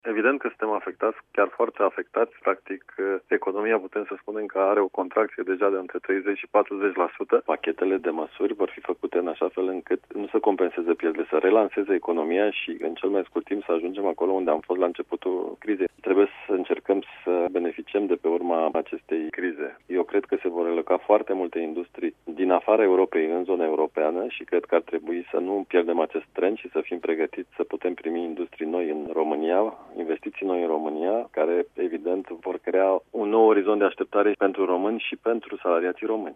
El a declarat, la Radio România Actualităţi, că sectorul auto, turismul, transporturile, şi aşa-numita industrie a ospitalităţii sunt cele mai afectate de restricţiile impuse de autorităţi.
Guvernul pregăteşte, însă, noi instrumente de sprijin pentru mediul de afaceri, printre care un ajutor direct de 750 de milioane de euro destinat întreprinderilor mici şi mijlocii, a subliniat ministrul Virgil Popescu: